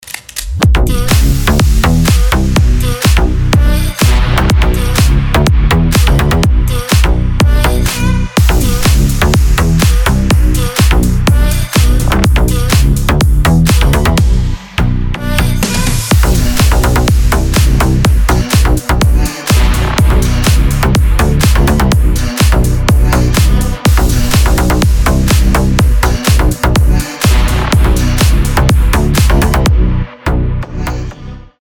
• Качество: 320, Stereo
мощные
качающие
electro house
G-House
Стильная клубная музыка на звонок